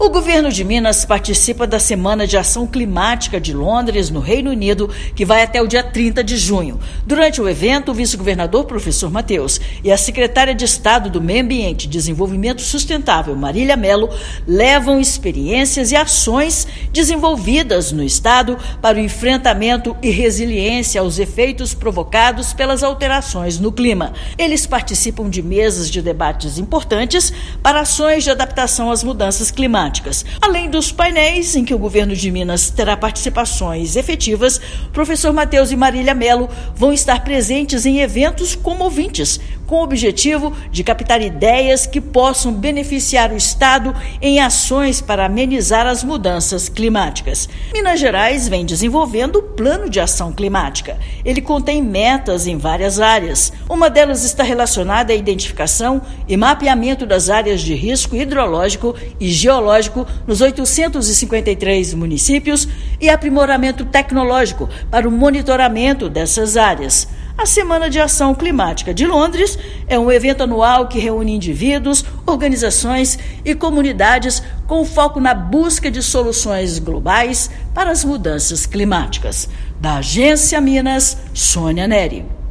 [RÁDIO] Governo de Minas apresenta ações e participa de debates na Semana de Ação Climática de Londres
Comitiva do estado integra mesas de discussões relevantes para as ações de adaptação às mudanças climáticas. Ouça matéria de rádio.